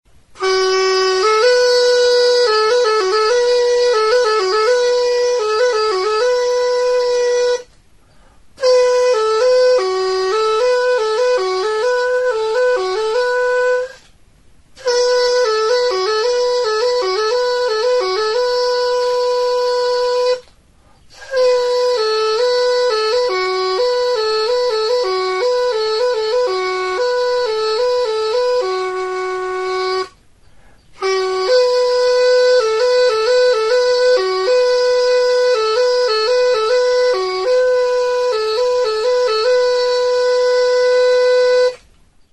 Enregistré avec cet instrument de musique.
TURUTA; OLO ZUZTARRA; GARI ZUZTARRA
Aérophones -> Anches -> Double (hautbois)
Gari-olo zuztarraren ordez, edateko plastikozko lastotxoarekin (pajita) egindako turuta da. Tonu aldaketarako 2 zulo ditu.